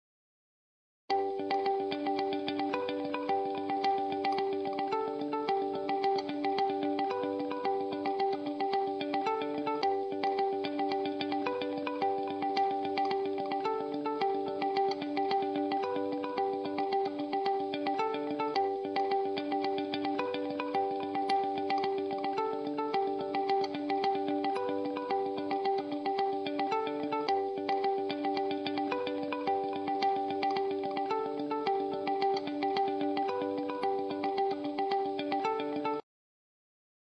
描述：一个吉他谐波回路与立体声110BPM同步延迟G键。
Tag: 清洁 谐波 边缘 吉他